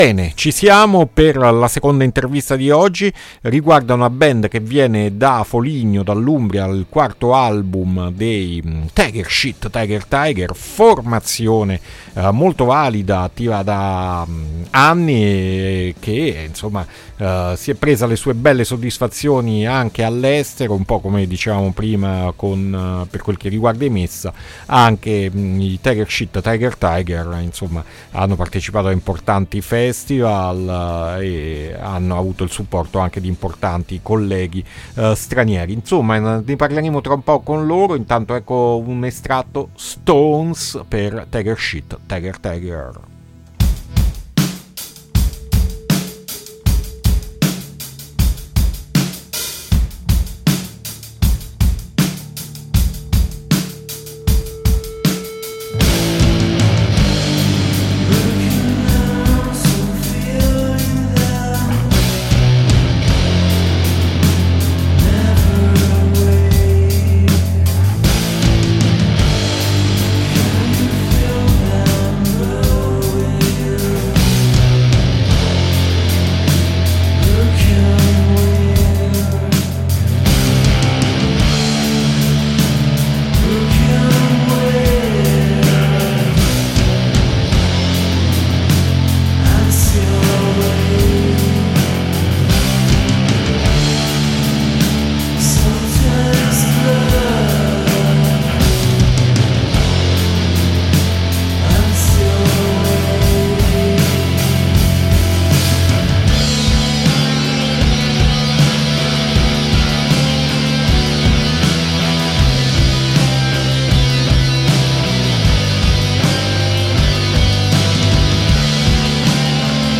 INTERVISTA TIGER!